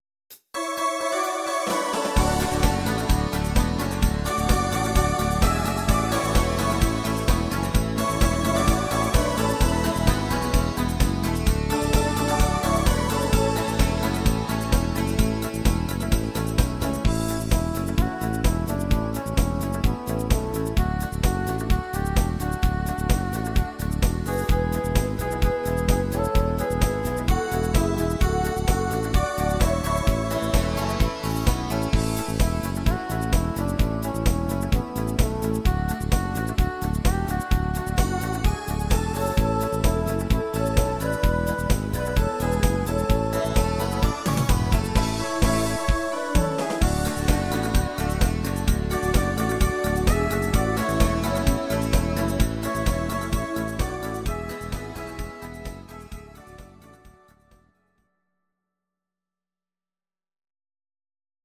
Audio Recordings based on Midi-files
German, Volkstï¿½mlich